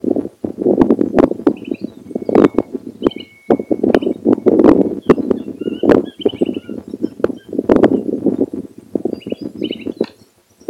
Batitú (Bartramia longicauda)
Pareja de batitú (Bartramia longicauda) que pasó volando sobre el campo.
Partido de Saladillo, Provincia de Buenos Aires.
Nombre en inglés: Upland Sandpiper
Localización detallada: Campo abierto
Certeza: Observada, Vocalización Grabada